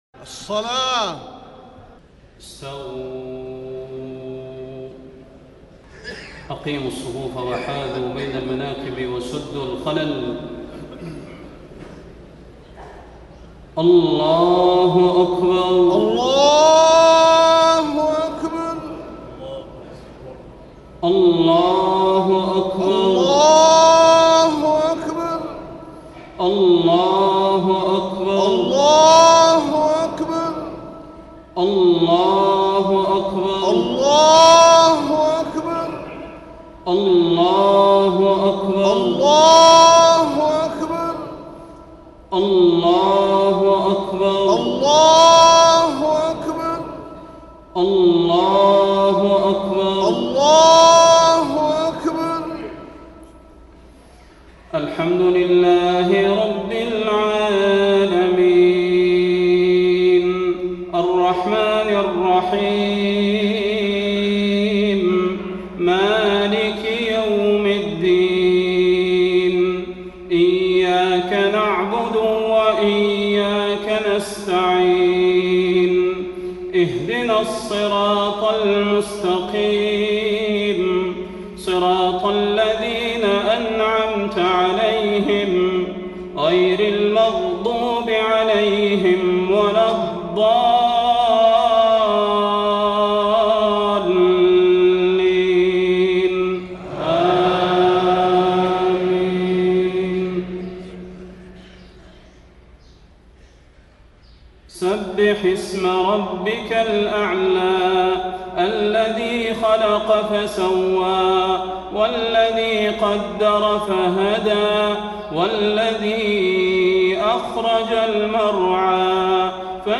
خطبة عيد الفطر - المدينة - الشيخ صلاح البدير
تاريخ النشر ١ شوال ١٤٢٥ هـ المكان: المسجد النبوي الشيخ: فضيلة الشيخ د. صلاح بن محمد البدير فضيلة الشيخ د. صلاح بن محمد البدير خطبة عيد الفطر - المدينة - الشيخ صلاح البدير